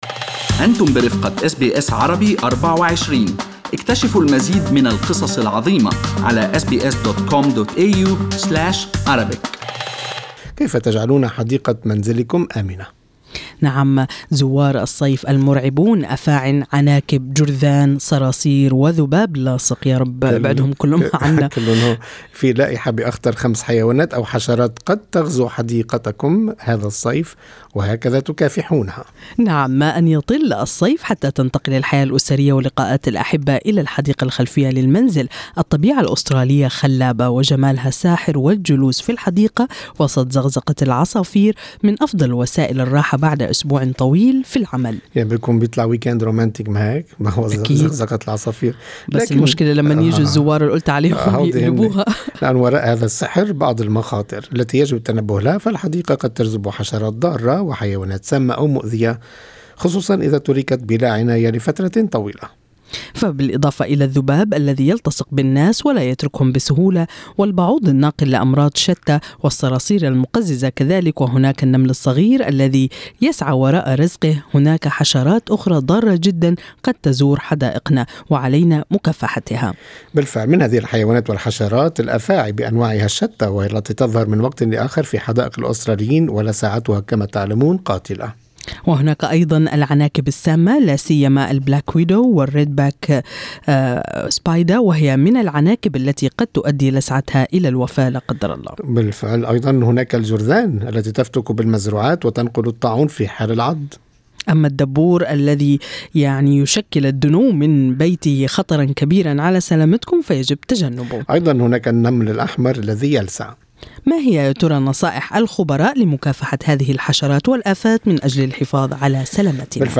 المقابلة